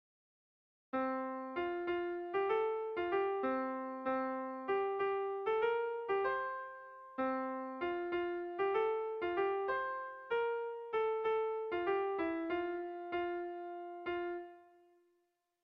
Gabonetakoa
AB